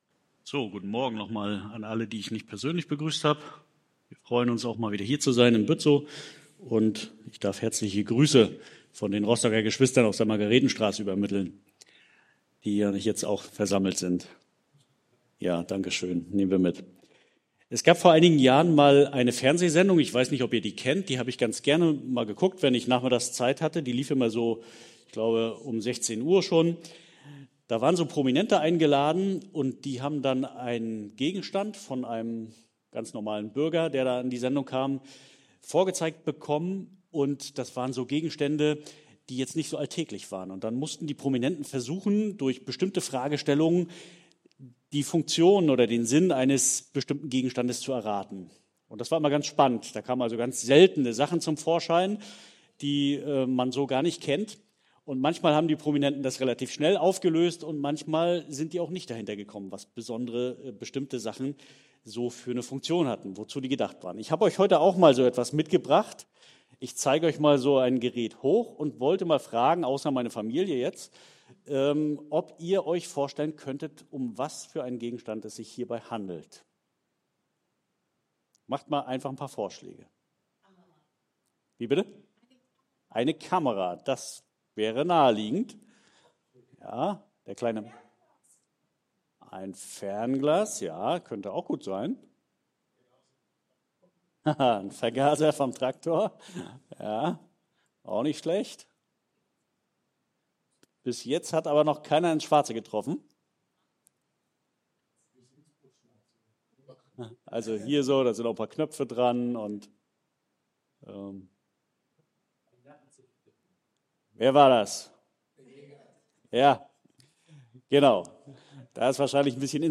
Baptisten Bützow: Predigten